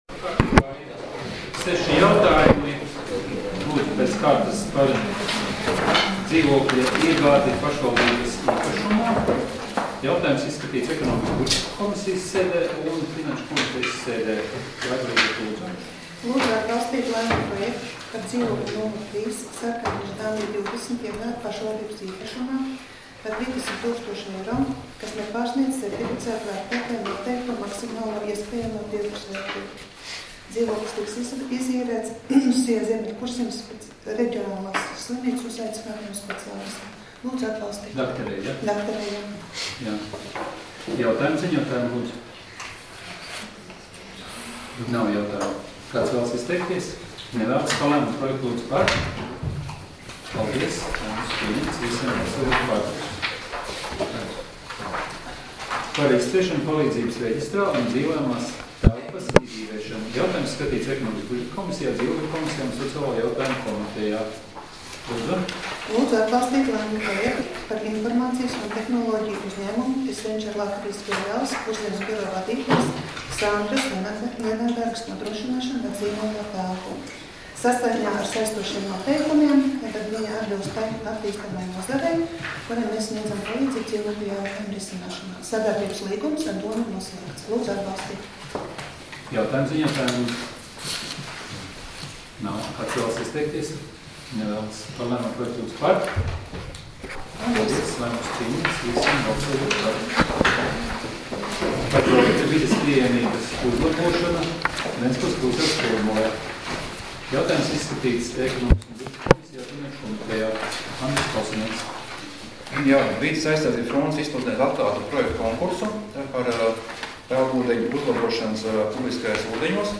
Domes sēdes 30.01.2018. audioieraksts